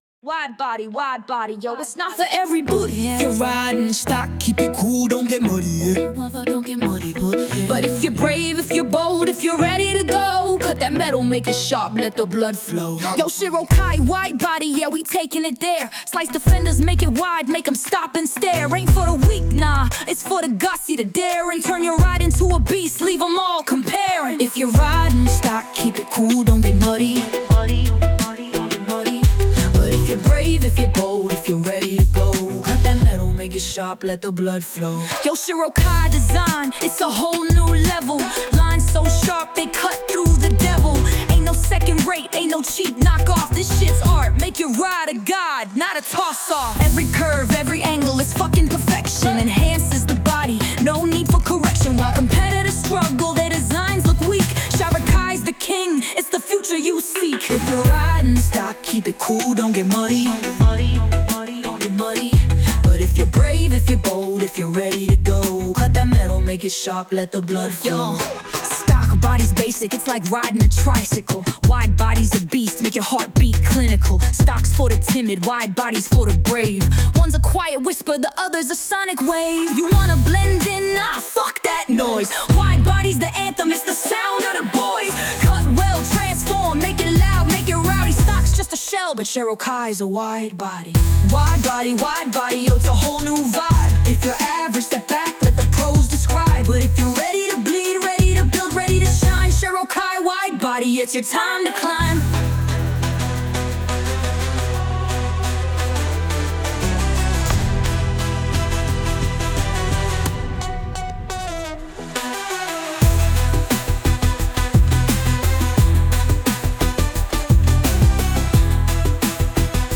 RnB Girl voice